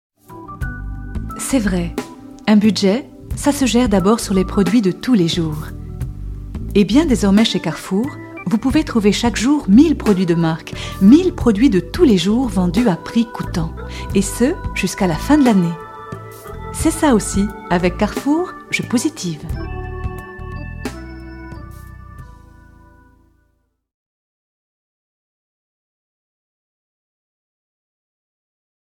VOIX-OFF AUDIOGUIDES, E-LEARNING, DOCUMENTAIRES, INSTUTIONNELS
Sprechprobe: Sonstiges (Muttersprache):